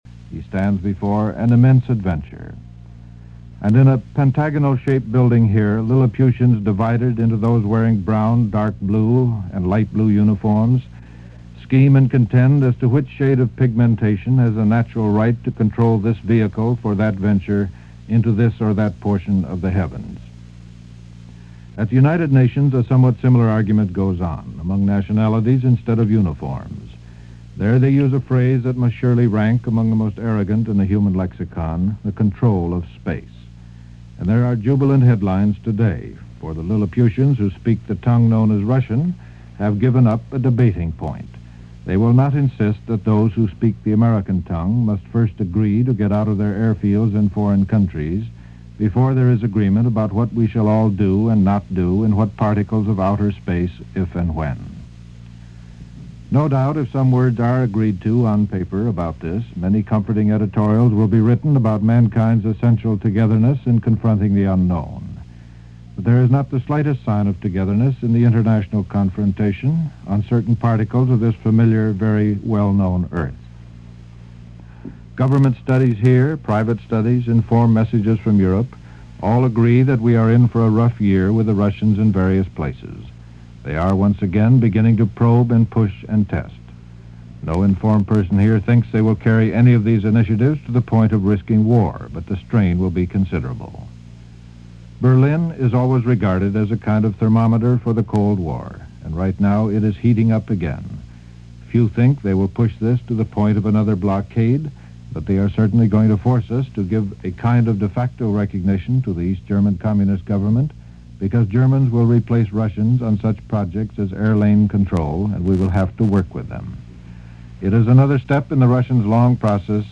World Of The Nervous Tick - November 1958 - Past Daily After Hours Reference Room - Erich Sevareid commentary.